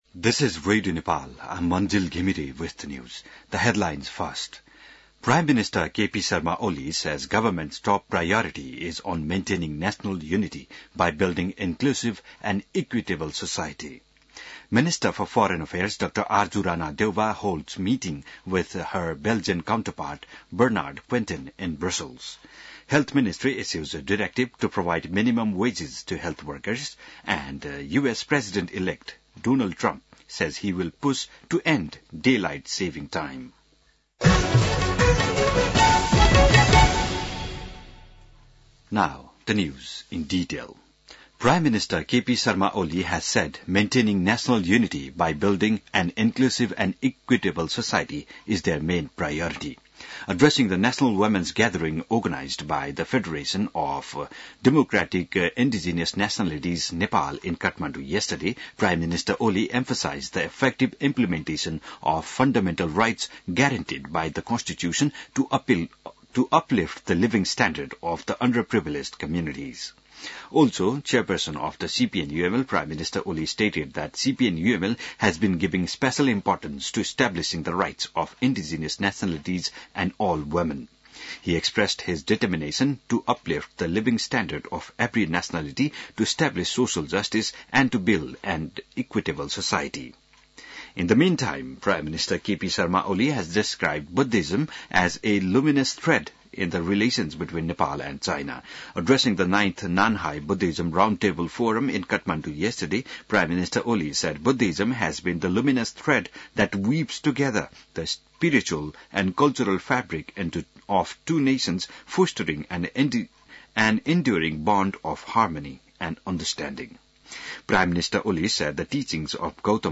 बिहान ८ बजेको अङ्ग्रेजी समाचार : ३० मंसिर , २०८१